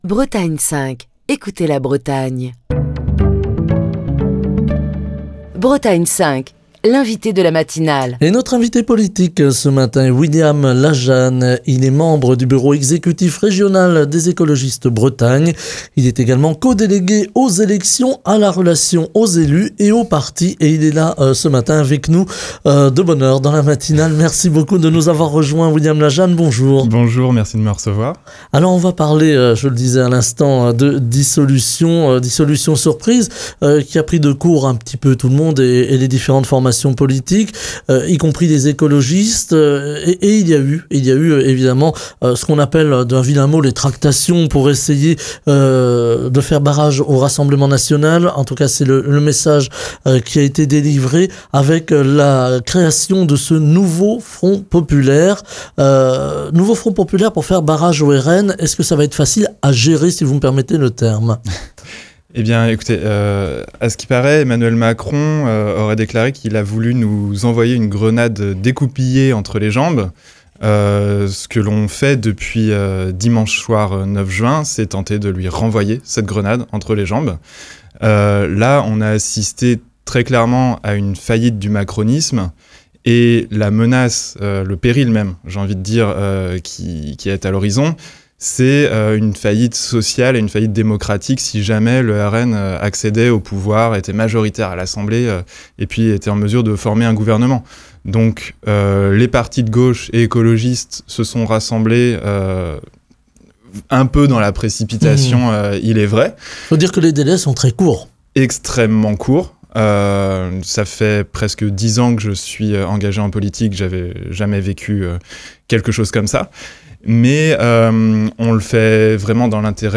l'invité de la matinale de Bretagne 5